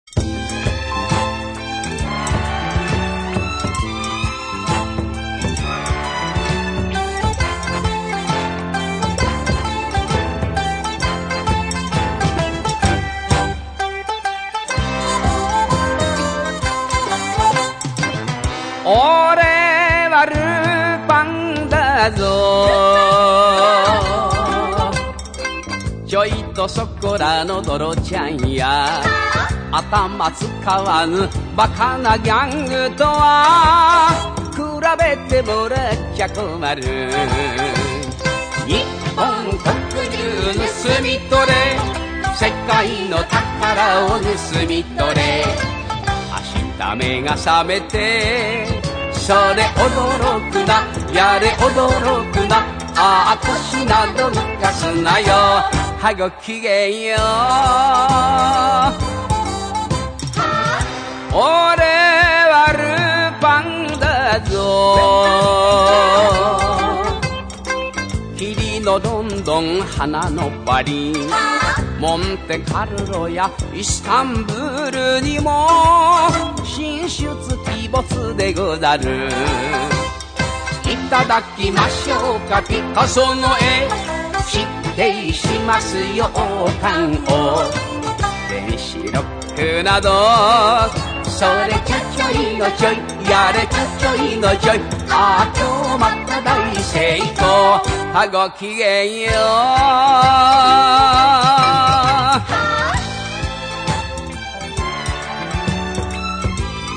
japon (traditionnel)
2° serie TV (ending song)